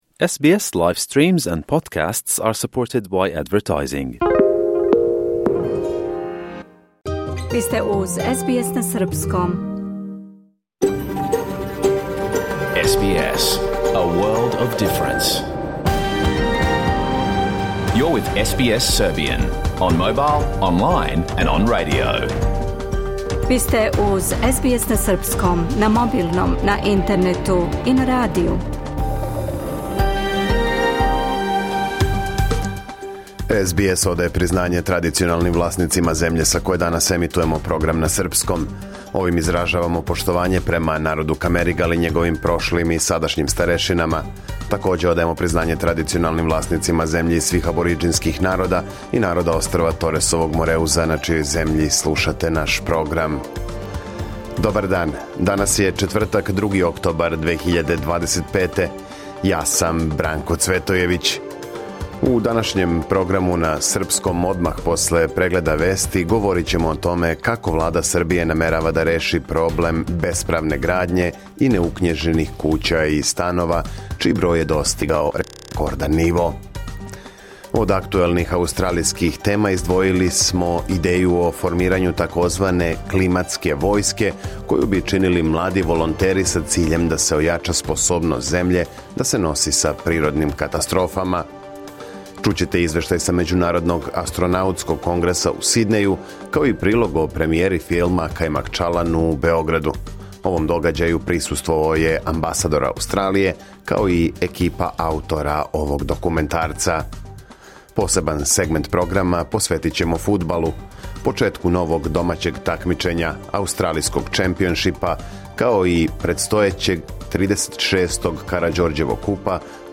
Програм емитован уживо 2. октобра 2025. године